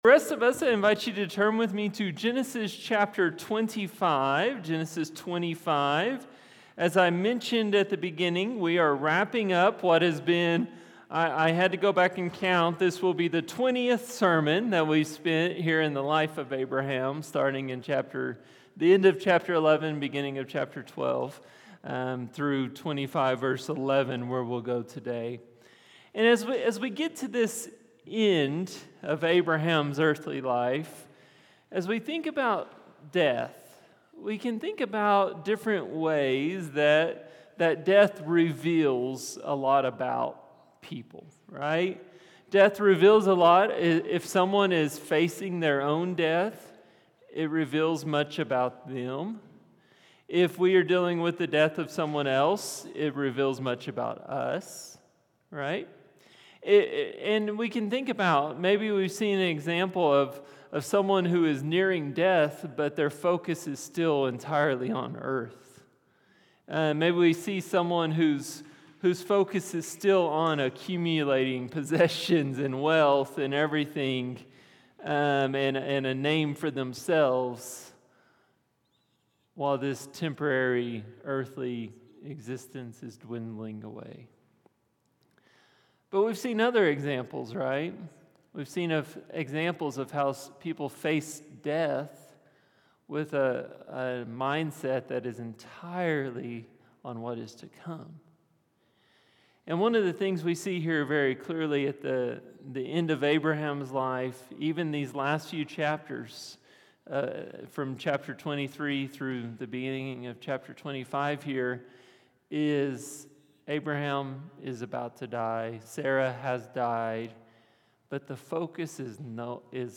A message from the series "Genesis 12-25."